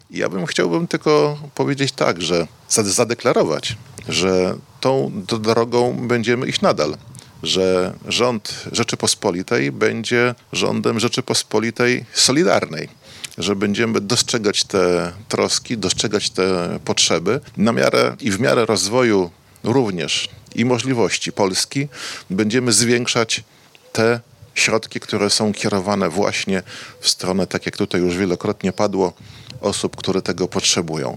O przyszłych planach pomocy poseł Leszek Dobrzyński:
STAR-konferencja-dofinansowanie-DOBRZYNSKI.mp3